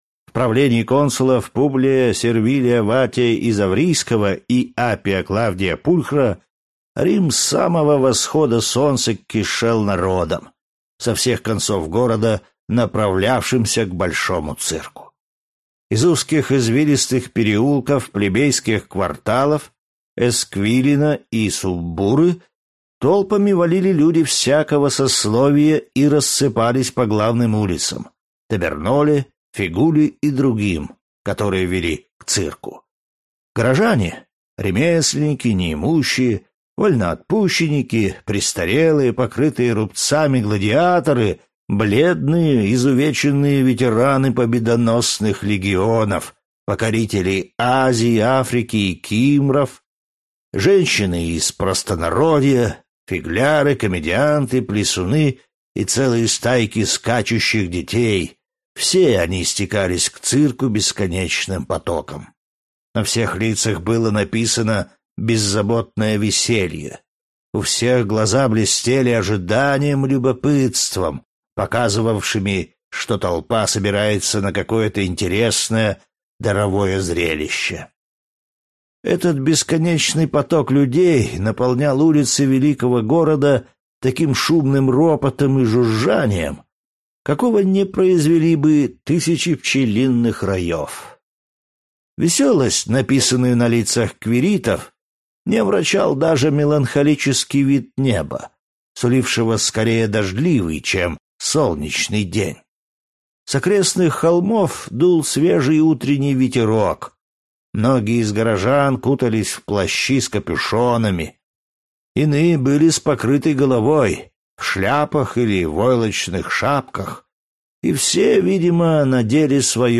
Аудиокнига Спартак | Библиотека аудиокниг